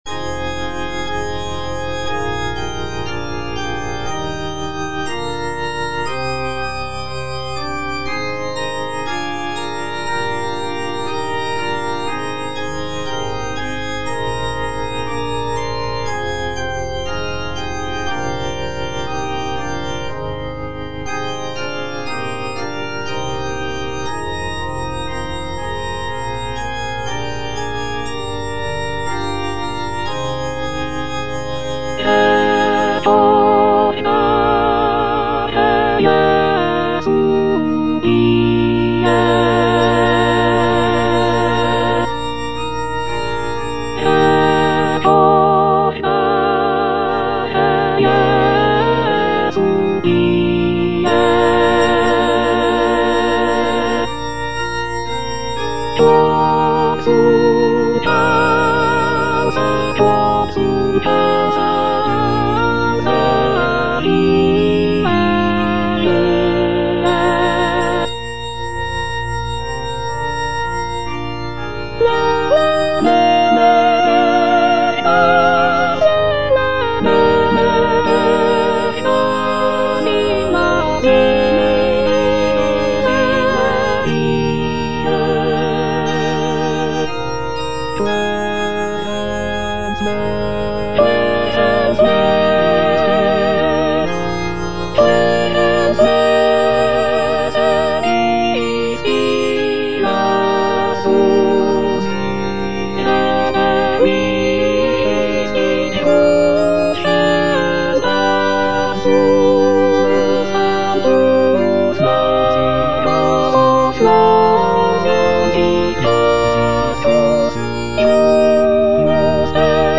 Soprano (Emphasised voice and other voices) Ads stop
is a sacred choral work rooted in his Christian faith.